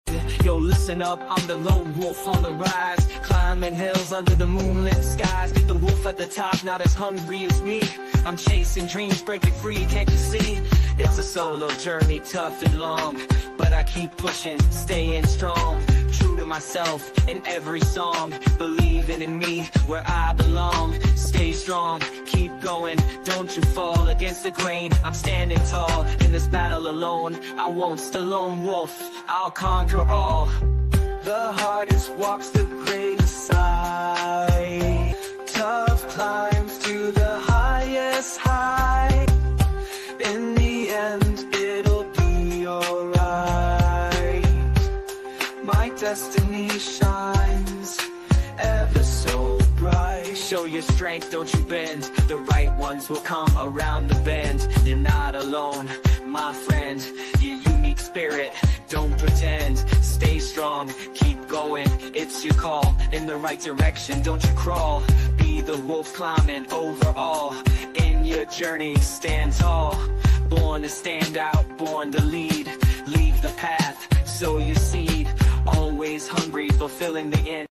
HipHop